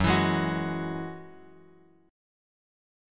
F#7sus2 chord {2 x 2 1 x 2} chord